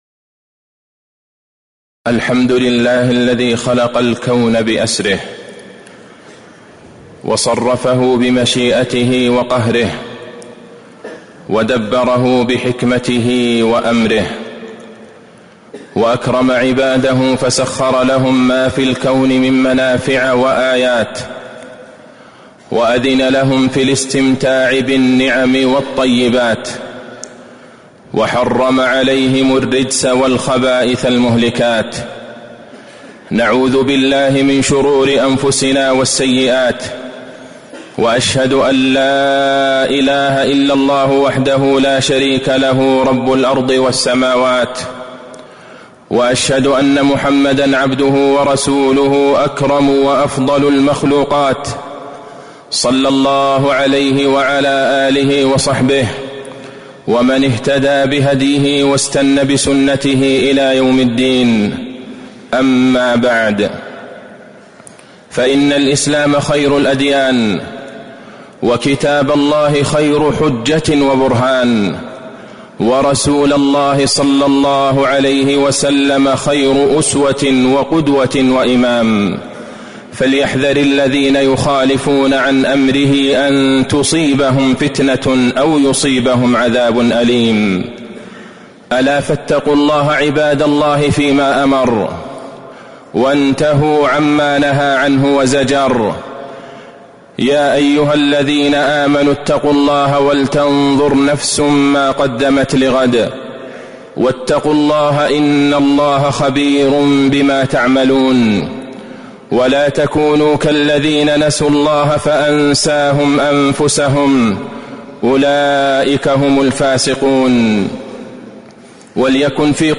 تاريخ النشر ١٥ شوال ١٤٤٤ هـ المكان: المسجد النبوي الشيخ: فضيلة الشيخ د. عبدالله بن عبدالرحمن البعيجان فضيلة الشيخ د. عبدالله بن عبدالرحمن البعيجان المخدرات أم الخبائث والرجس